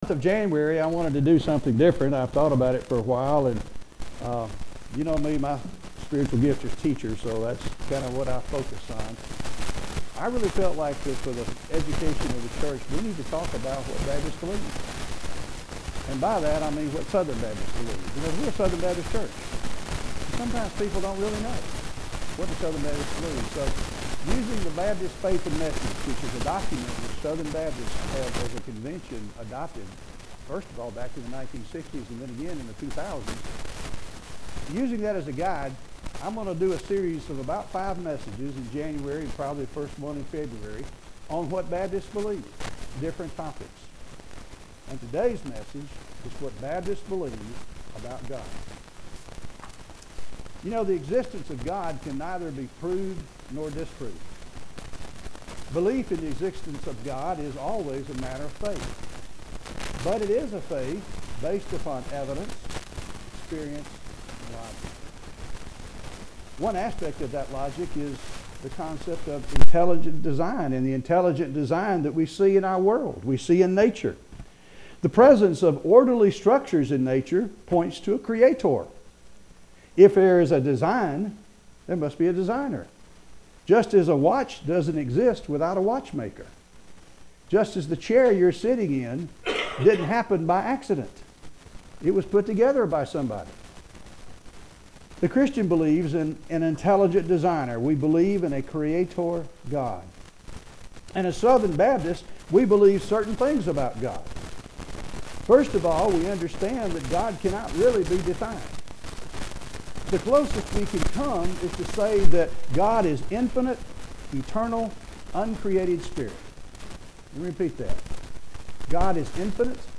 The recording does have some static.